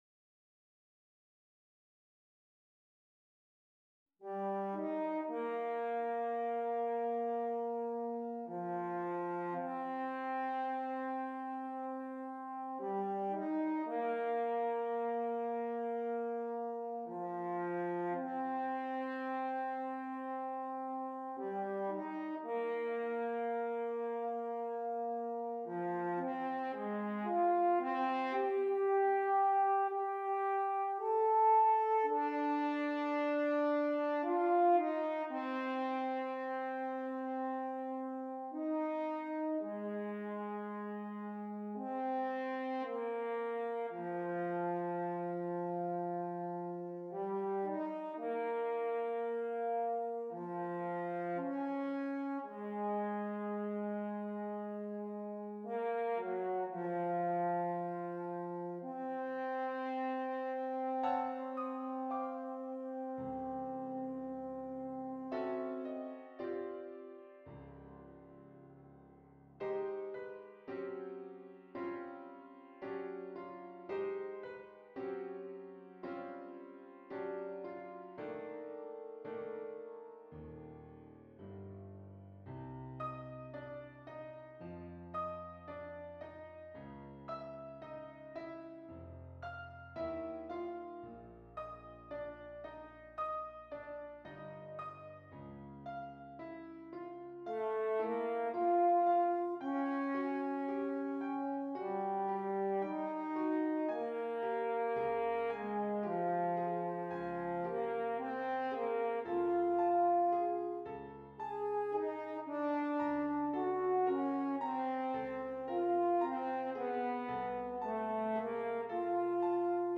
2 F Horns and Keyboard